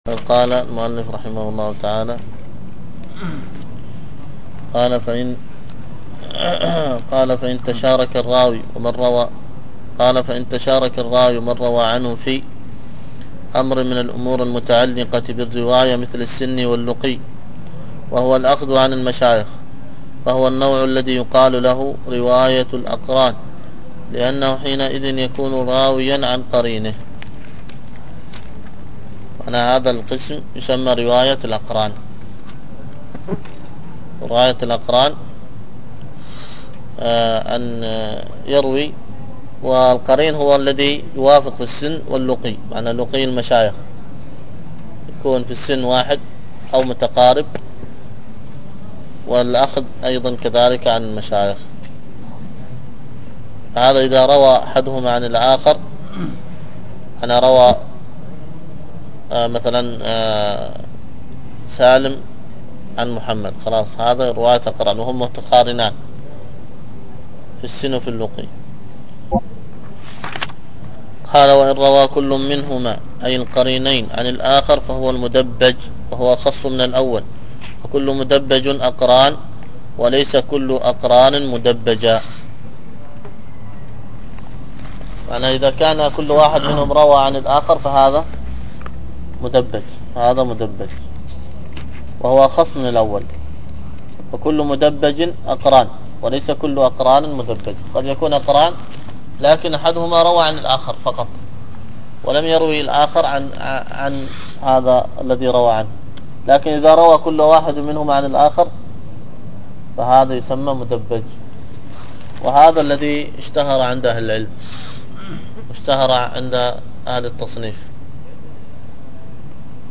الدرس في الصحيح المسند مما ليس في الصحيحين 170، ألقاها